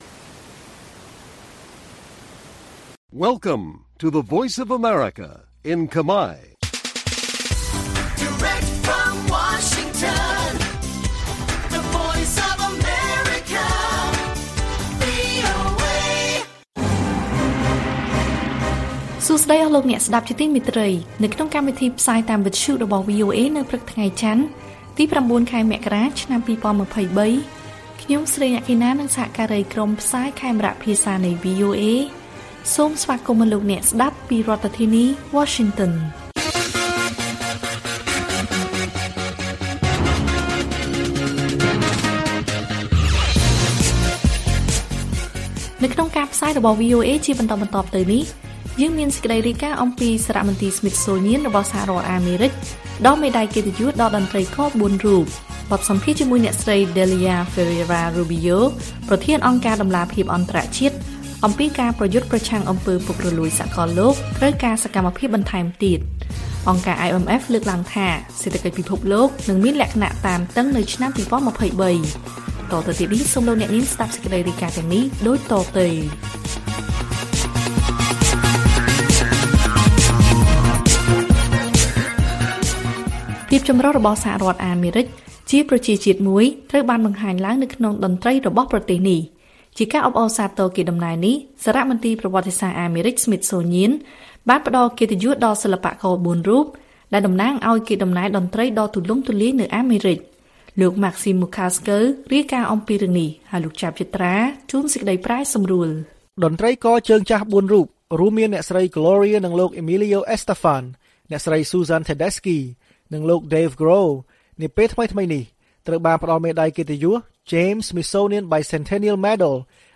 ព័ត៌មានថ្ងៃនេះមានដូចជា សារមន្ទីរ Smithsonian ផ្តល់មេដាយកិត្តិយសដល់តន្រ្តីករ ៤រូប។ បទសម្ភាសន៍ជាមួយអ្នកស្រី Delia Ferreira Rubio ប្រធានអង្គការតម្លាភាពអន្តរជាតិ អំពី«ការប្រយុទ្ធប្រឆាំងអំពើពុករលួយសាកលលោកត្រូវការសកម្មភាពបន្ថែមទៀត» និងព័ត៌មានផ្សេងៗទៀត៕